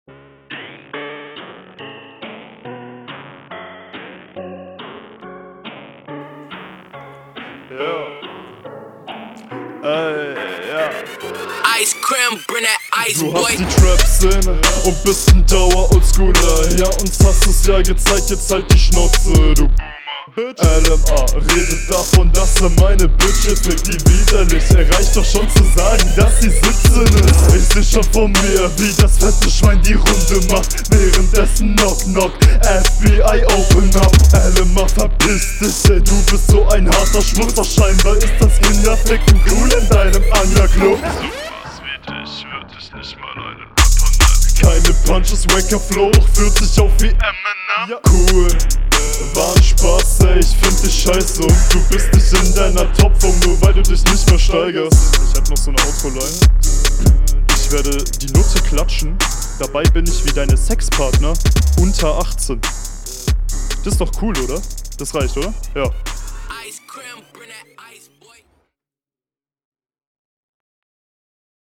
Du setzt deine Stimme richtig stark ein, und ich muss mal …